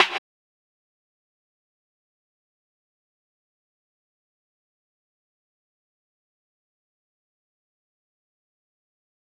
JJSnares (35).wav